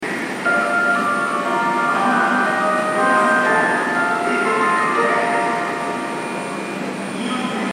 新高島駅　Shin-Takashima Station ◆スピーカー：National天井型
2番線発車メロディー